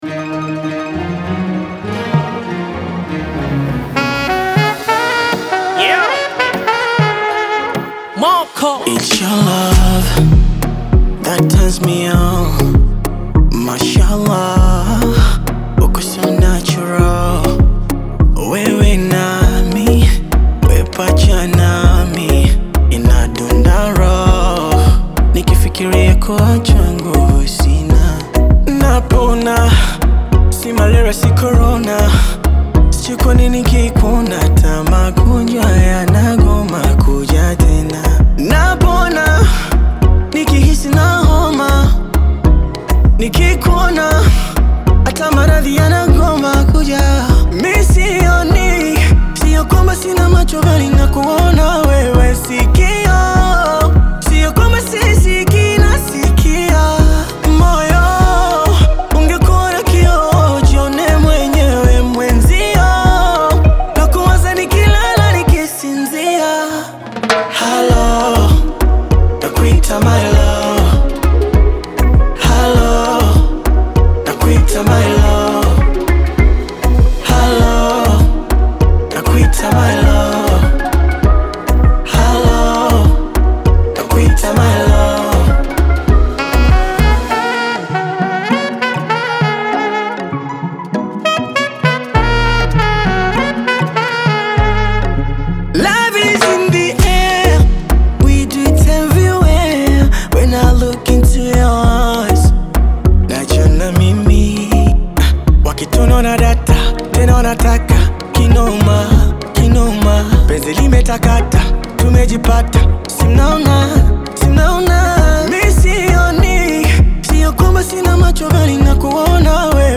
a dynamic and catchy tune